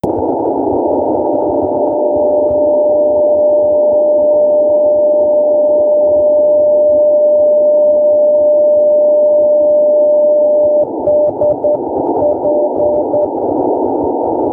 The transmission, repeated in variable periods of time, consists of the emission of a carrier lasting several seconds followed by a series of letters issued in Morse code. Often manufactured according to poor designs and /or using low-quality materials, many of them drift in frequency and transmit a poor quality signal.
CW-A1A-fishing-buoy-1.wav